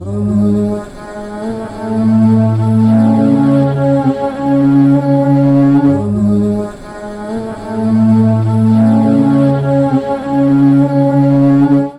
sirenssong.wav